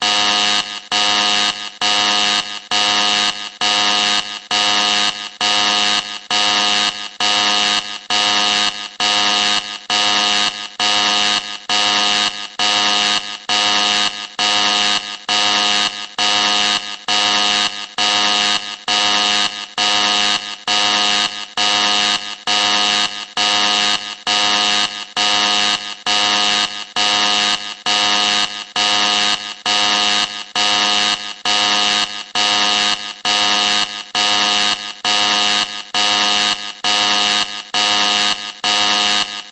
ブザー音。
警報音-01 着信音
試聴とダウンロード ブザー音。早く逃げなくては危険です。